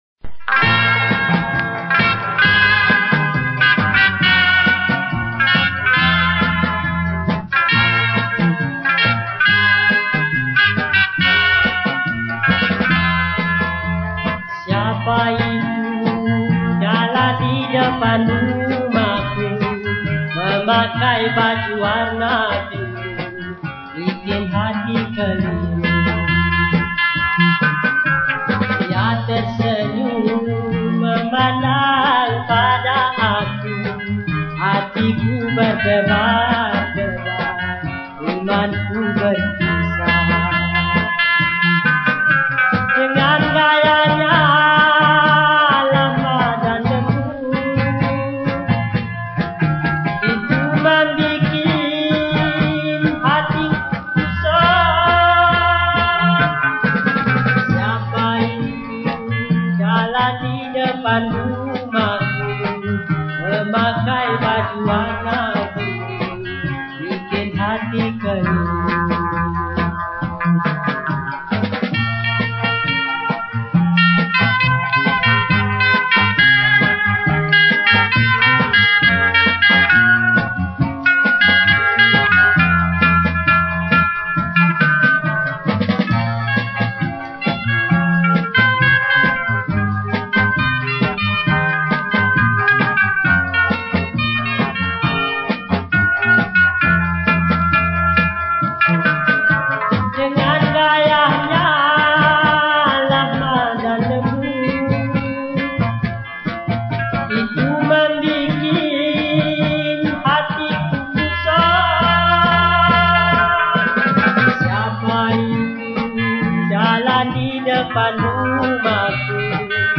Pop Yeh Yeh